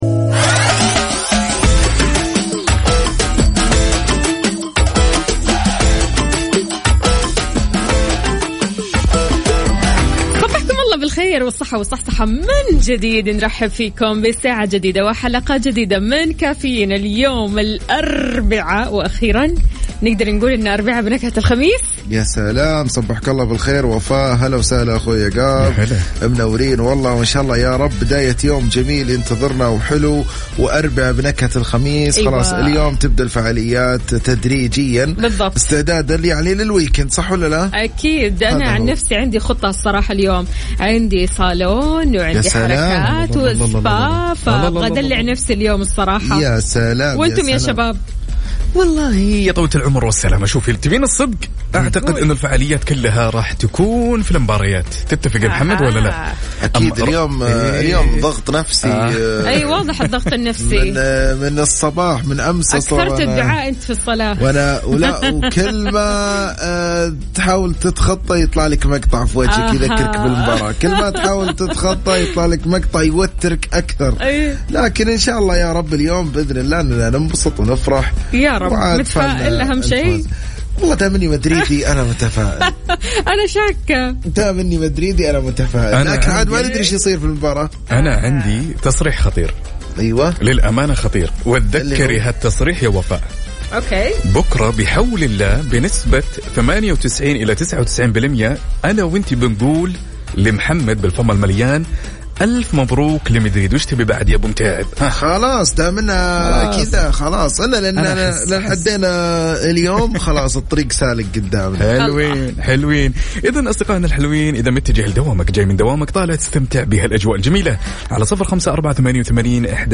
البرنامج إنطلاقة صباحية منعشة تفتح نافذتها على مختلف الأخبار العالمية والمحلية والموضوعات الإجتماعية والثقافية الخفيفة في إطار حيوي وحماسي وتفاعلي مع المستمعين من خلال المسابقات يعطي نكهة مختلفة للصباح.